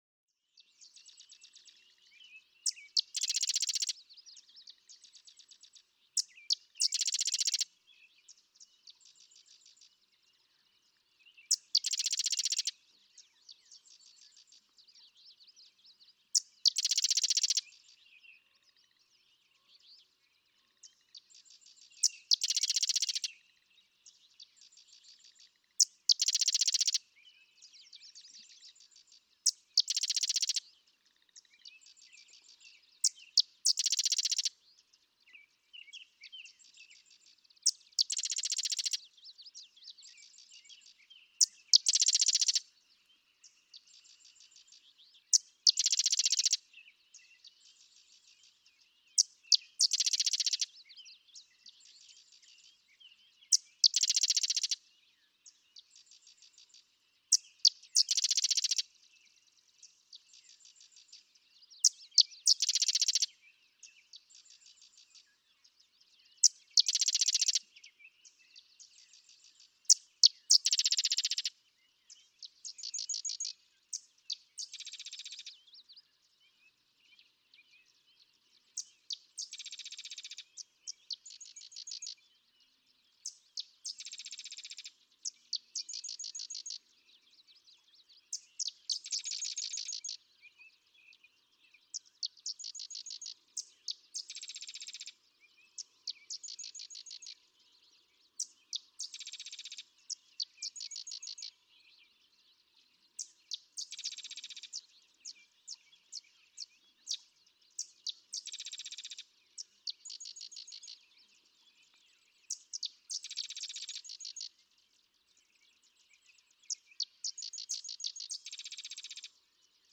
Improvising—sedge wren
Young males can improvise large repertoires of songs, creating a repertoire that is entirely different from other males, yet all with the same "theme," so each song is instantly recognizable as coming from a sedge wren.
Sax-Zim Bog, Minnesota.
652_Sedge_Wren.mp3